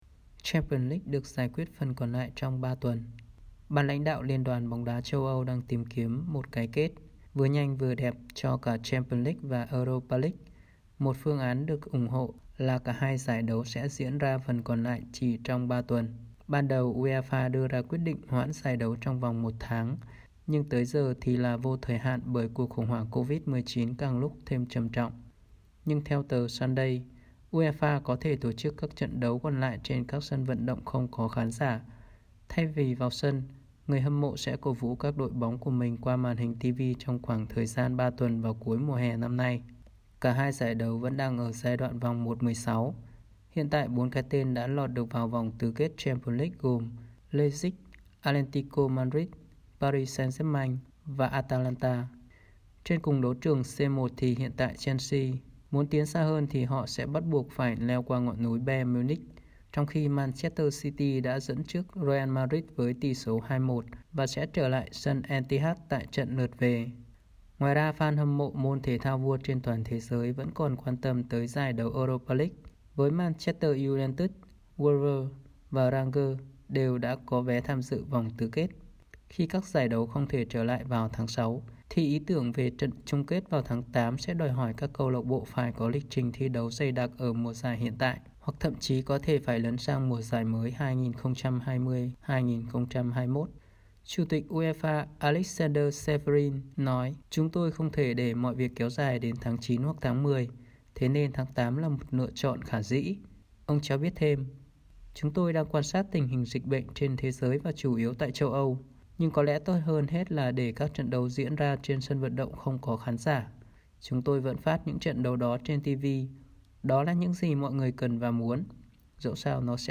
Bản tin audio